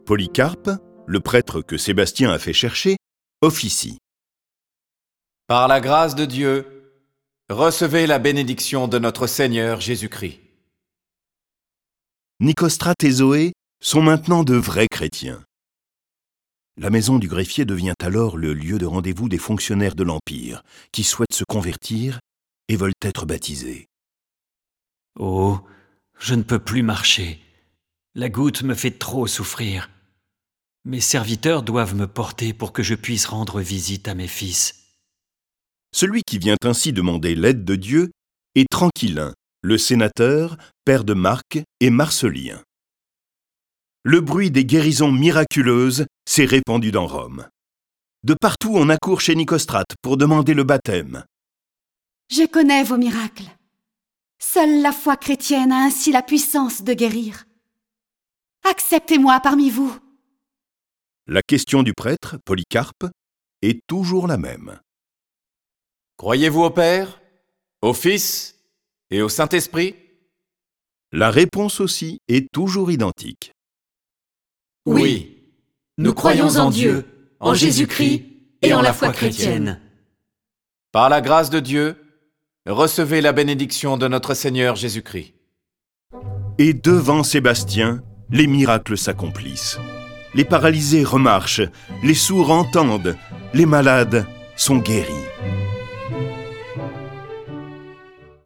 Diffusion distribution ebook et livre audio - Catalogue livres numériques
Cette version sonore de la vie de saint Sébastien est animée par dix voix et accompagnée de plus de trente morceaux de musique classique.